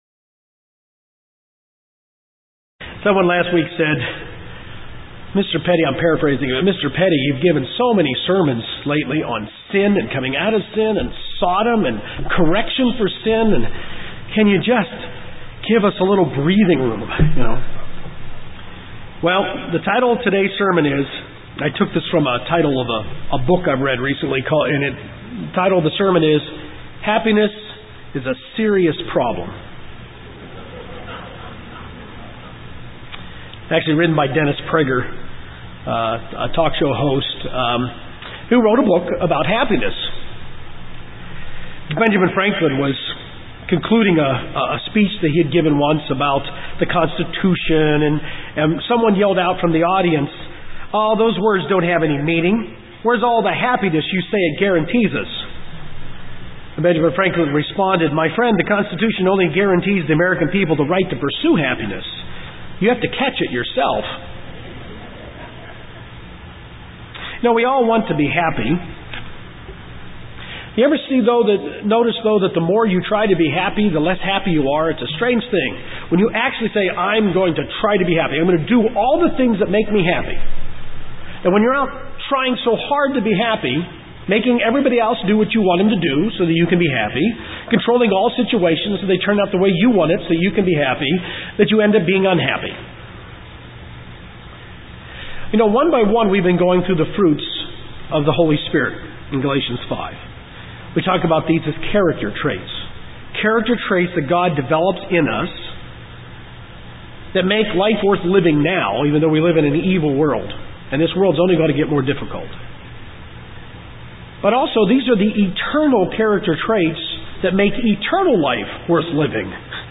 In this sermon the next in the series of sermons on the fruits of the spirit covers the fruit of joy. God expects us to learn all of the fruits of the spirit which includes Joy.